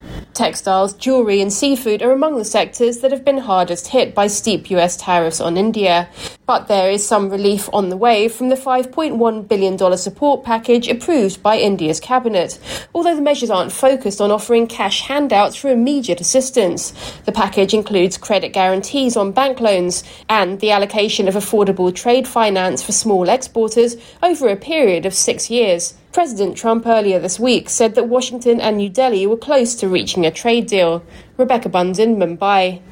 reports from Mumbai.